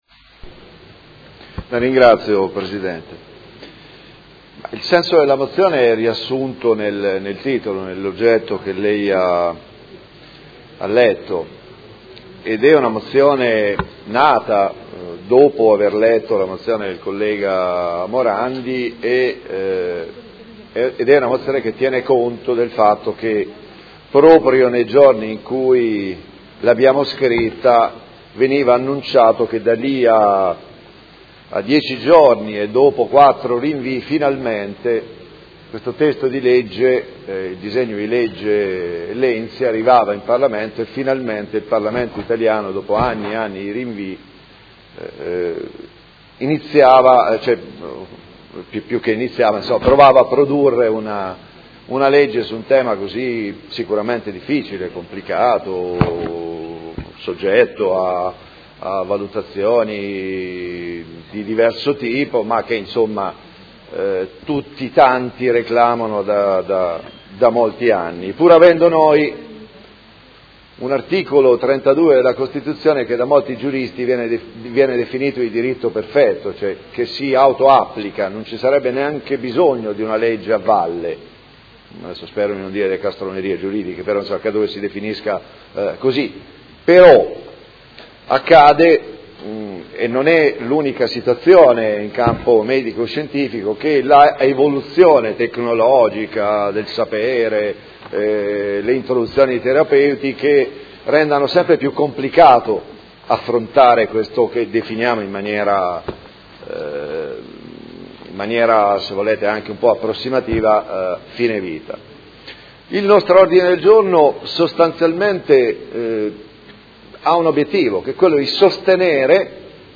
Seduta del 16/03/2017. Mozione presentata dal Gruppo Art. 1 – MDP e dal Gruppo Per me Modena avente per oggetto: Rendere, con una legge, pieno ed effettivo il principio della autodeterminazione nei trattamenti sanitari, sancito dall’art. 32 della Costituzione italiana, è un atto necessario di umanità e civiltà atteso da molti anni